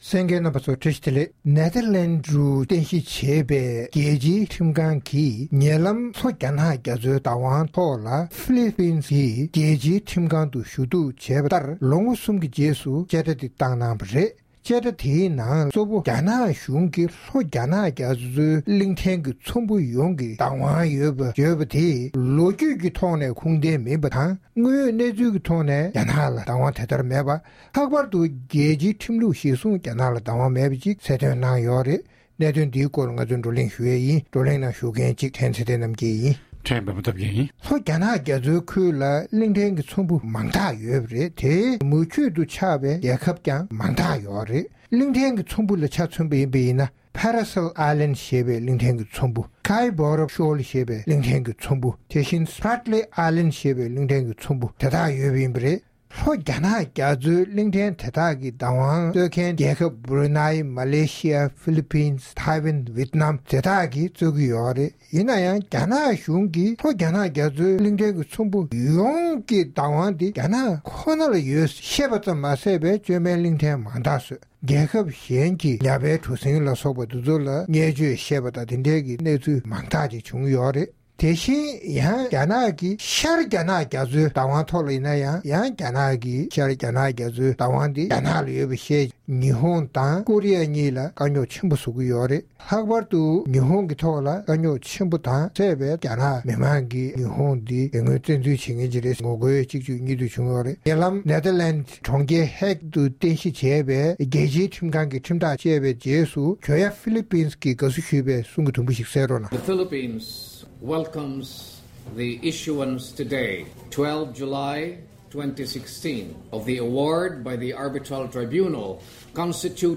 བགྲོ་གླེང་གནང་བ་ཞིག་གསན་རོགས།